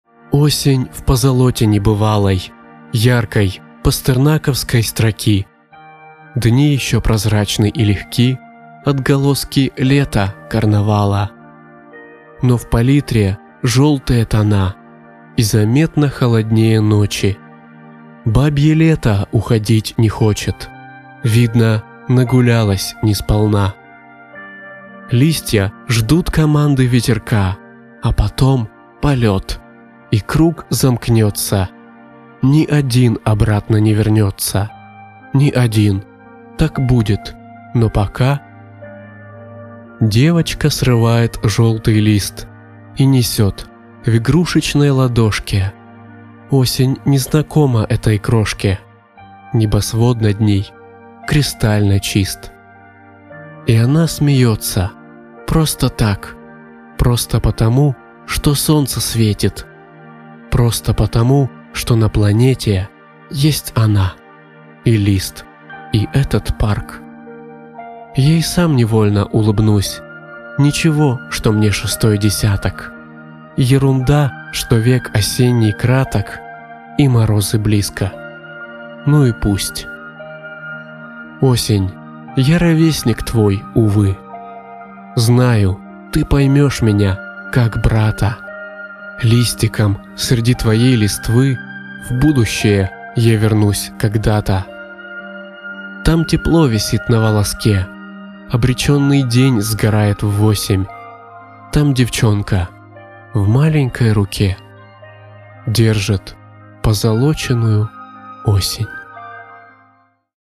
Озвучивание стихотворения победителя конкурса поэтов